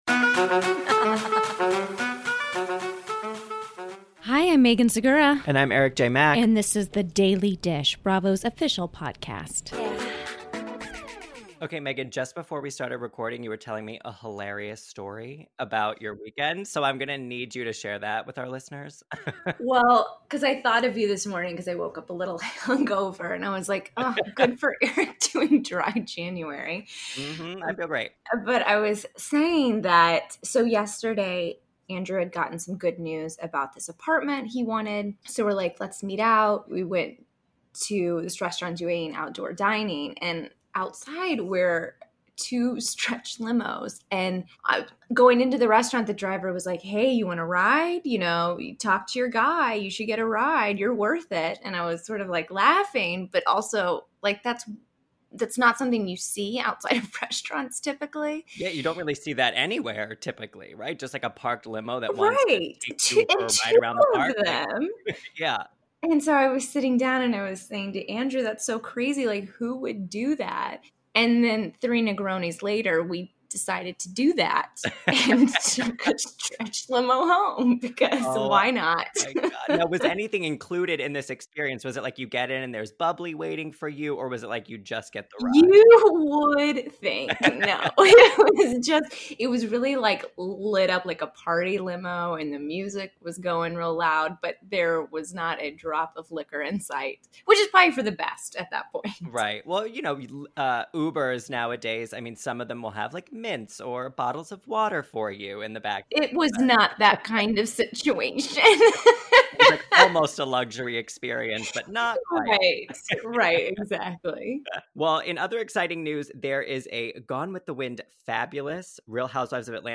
Plus, Fashion Queens alum Bevy Smith calls in to discuss her career, life, and new book, Bevelations: Lessons from a Mutha, Auntie, Bestie and weighs in on the newest Real Housewife of New York City, Eboni K. Williams.